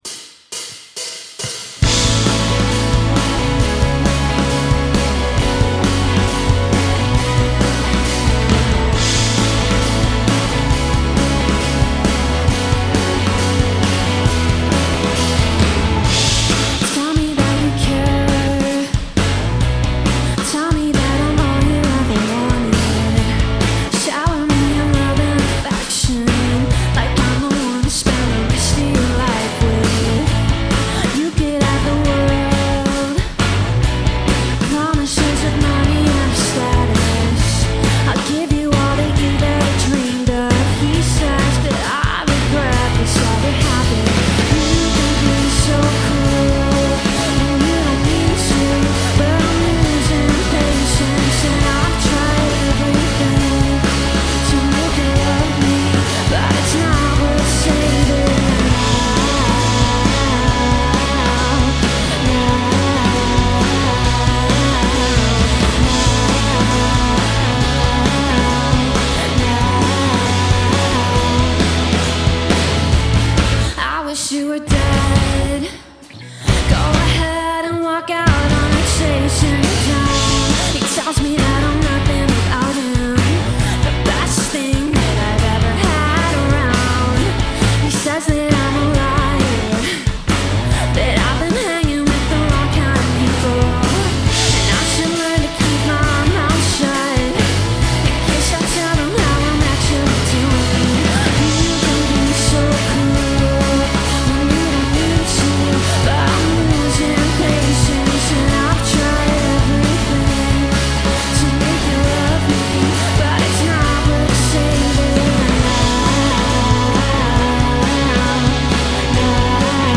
recorded at Nerve Centre, Derry
in session